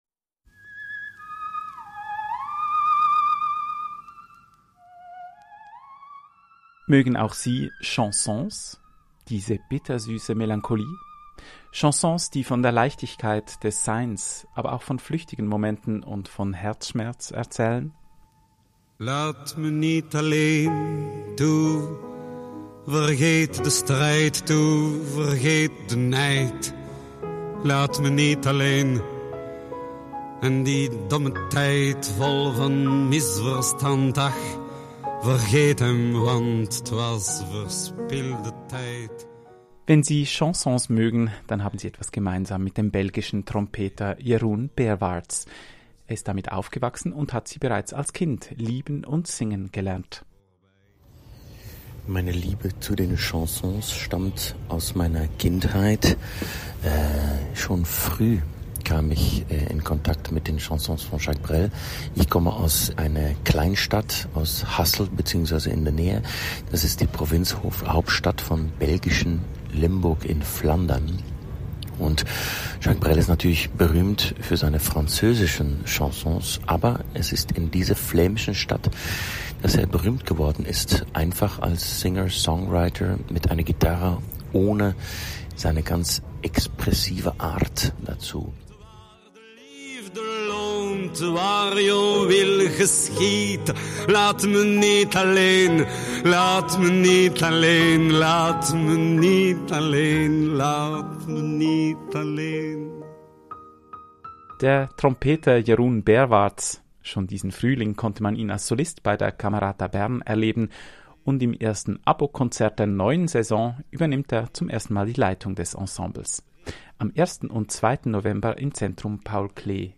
Zum Podcast der Konzerteinführung